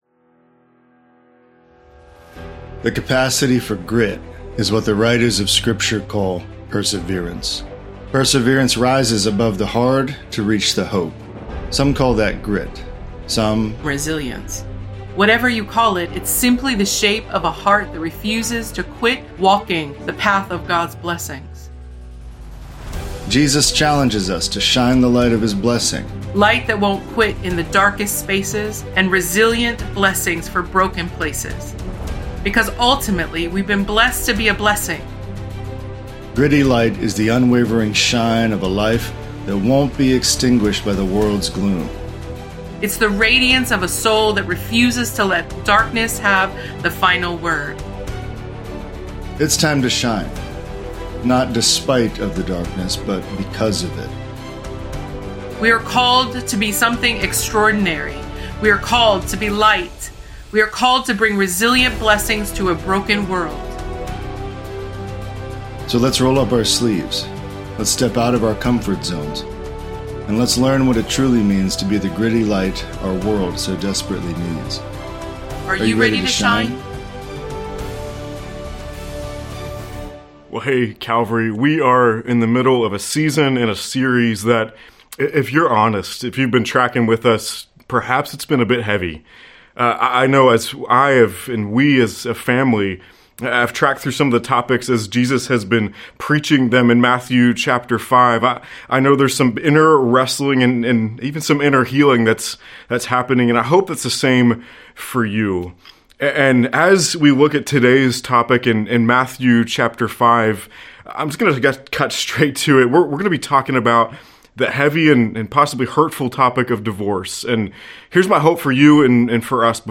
The sermon "Gritty Marriage" tackles the tough issue of divorce by looking at Jesus message in Matthew 5:31-32.